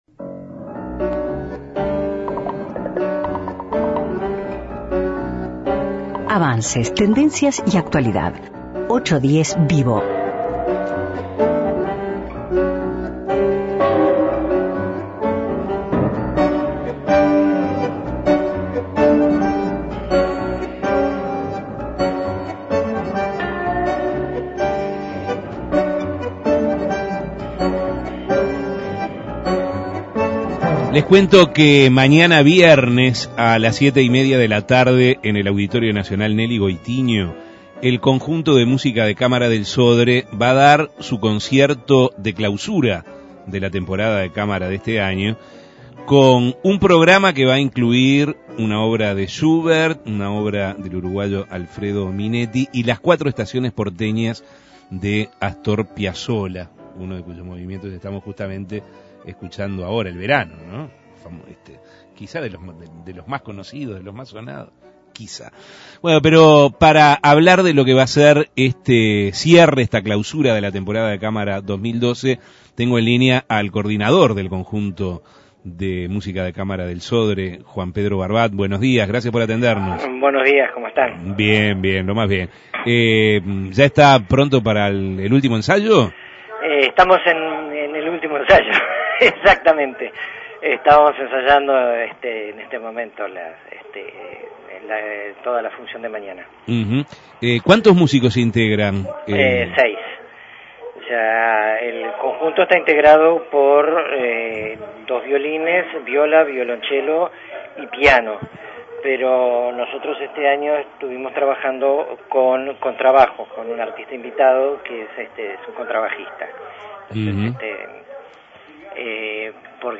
810VIVO Avances, tendencia y actualidad conversó con